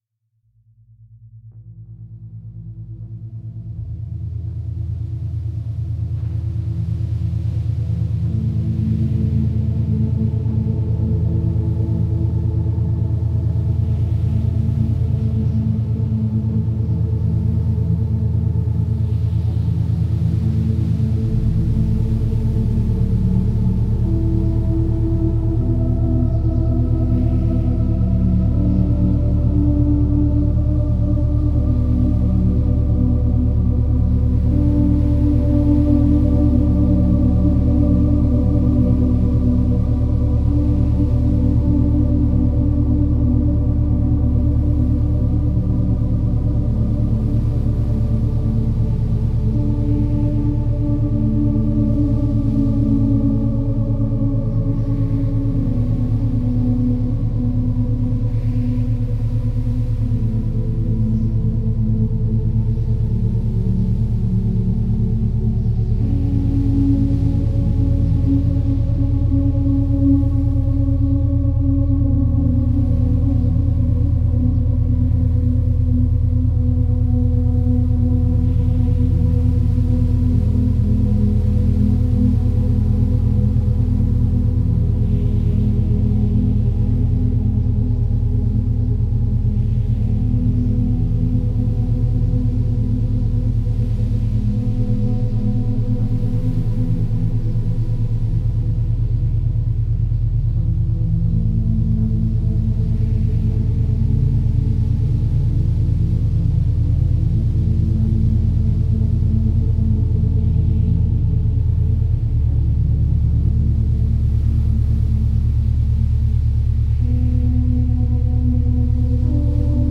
107Hz – 116Hz
در این فصل قرار هست با ضرباهنگ‌های دوگانه آشنا بشیم. گروهی از این فرکانس‌ها به خواب و آرامش شما کمک می‌کنن و گروه دیگشون برای افزایش تمرکز هستن، این امواج میتونن در حین مدیتیشن، حین انجام کار و یا قبل خواب گوش داده بشن.